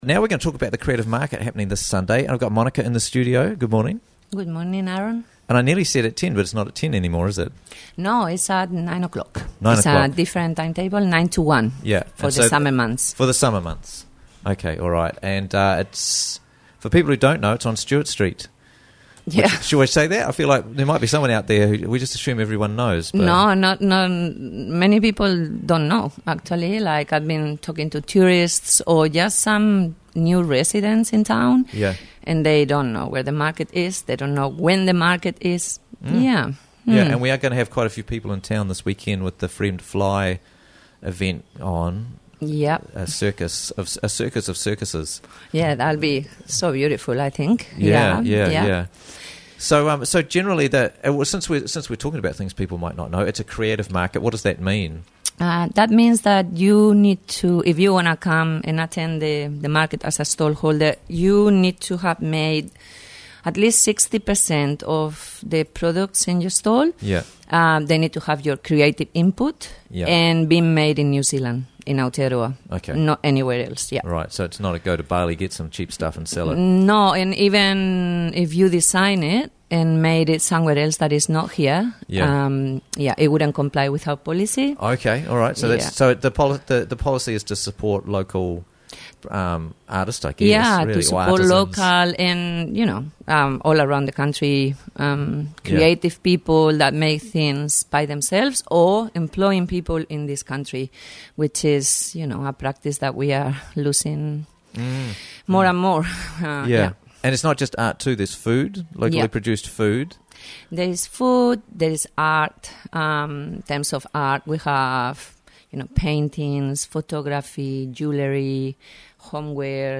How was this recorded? joins us in the studio